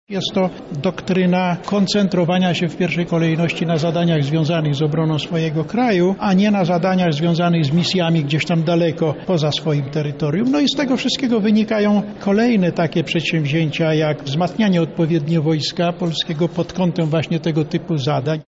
Jest to doktryna typowa dla kraju z obszarów granicznych NATO, a nie jego centrum – tak wdrażaną strategię komentuje sekretarz stanu i szef Biura Bezpieczeństwa Narodowego Stanisław Koziej